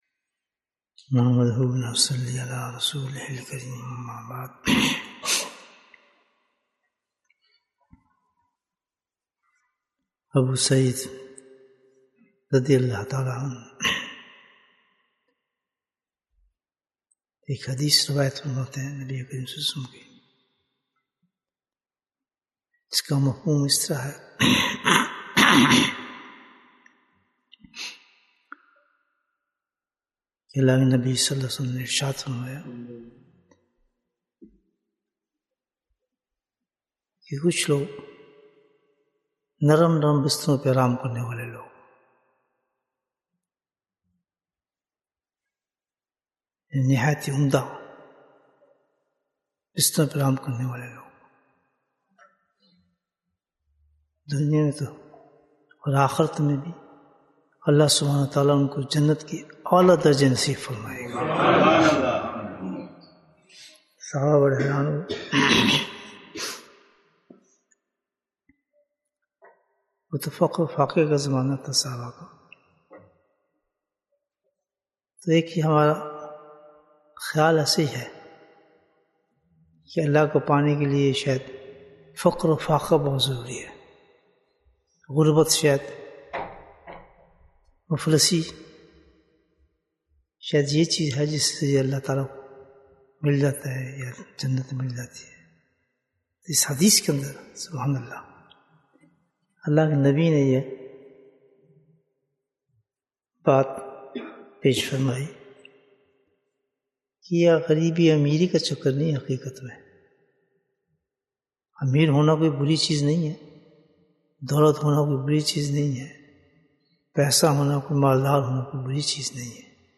Bayan, 22 minutes19th May, 2023